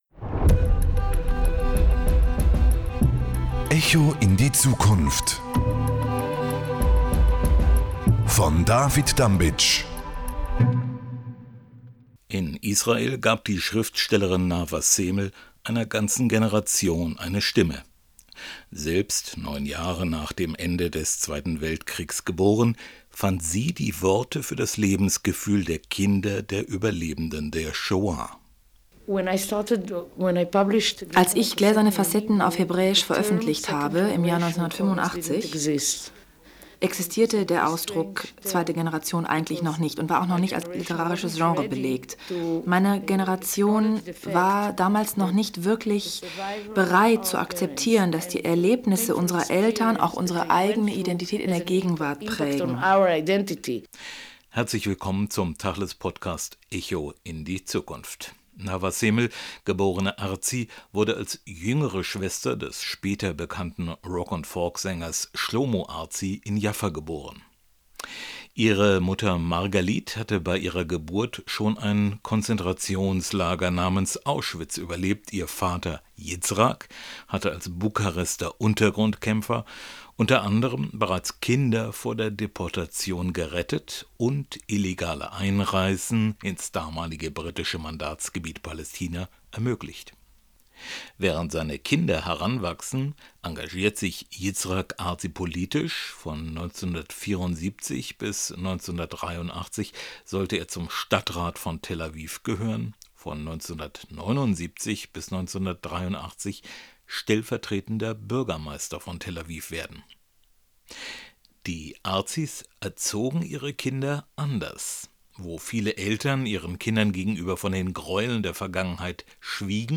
In Folge 15 von «Echo in die Zukunft» erzählt die israelische Schrifstellerin Nava Semel, wie sie mit ihrem Roman «Und die Ratte lacht» Vergangenheit, Gegenwart und Zukunft verbindet – auch als Libretto für eine Oper. Ein bewegendes Gespräch über Identität, Verantwortung und den künstlerischen Umgang mit dem Erbe der Shoah.